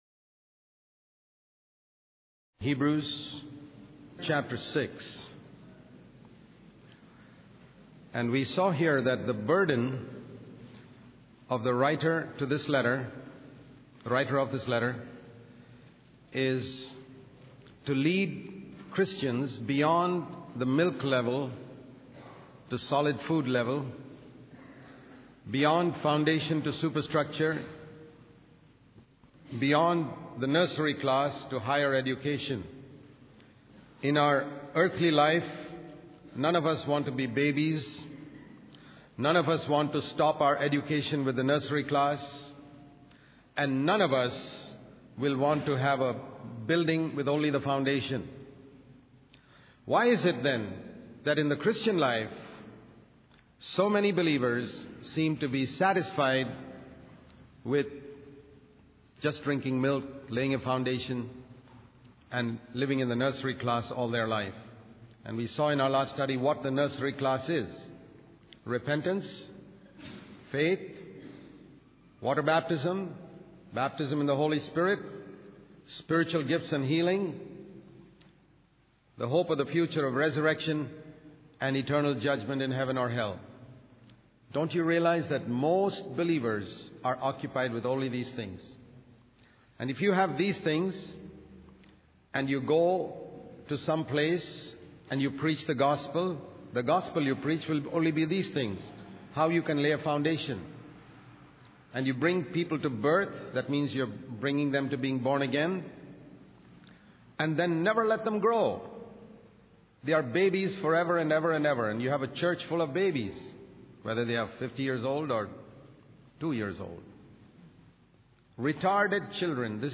In this sermon, the speaker emphasizes the importance of not being lazy in the Christian life. He shares a poem by A.B. Simpson that highlights the need for diligent work for God.